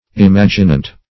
Search Result for " imaginant" : The Collaborative International Dictionary of English v.0.48: Imaginant \Im*ag"i*nant\, a. [L. imaginans, p. pr. of imaginari: cf. F. imaginant.]